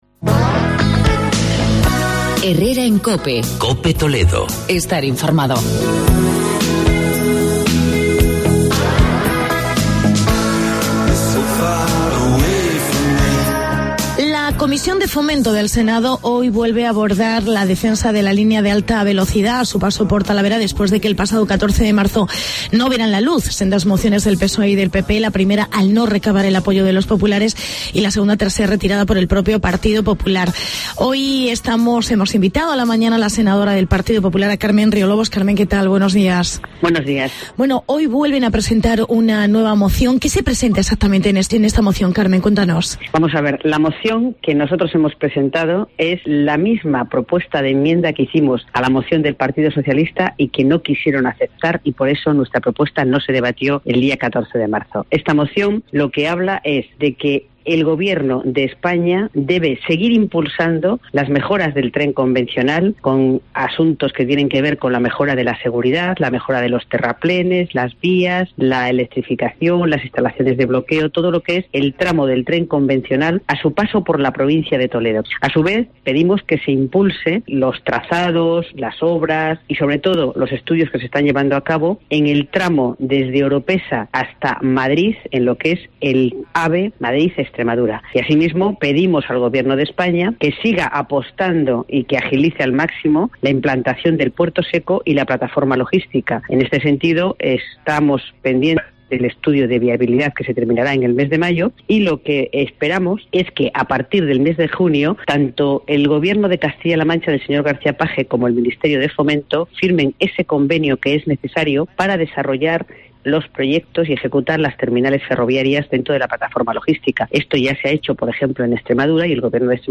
Entrevista con la senadora del PP: Carmen Riolobos